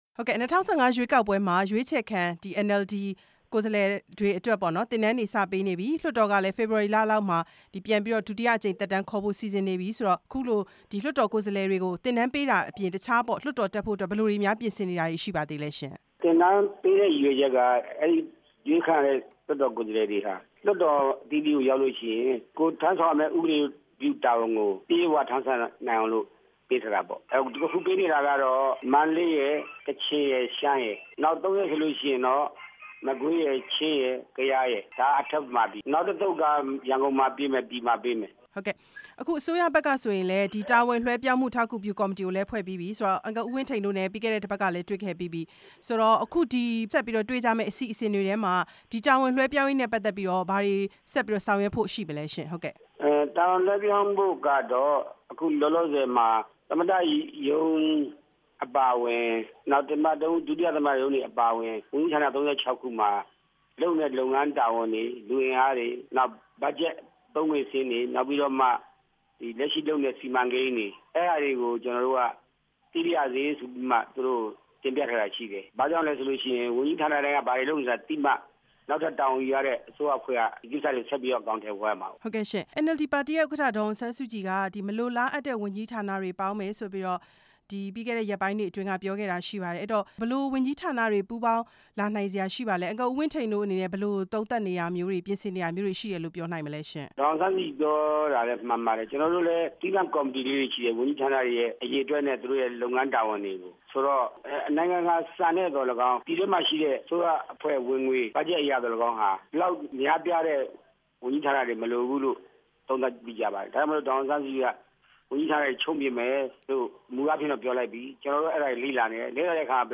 NLD ပါတီအစိုးရသစ်ဖဲ့ွစည်းရေး ပြင်ဆင်နေတဲ့ အခြေအနေ မေးမြန်းချက်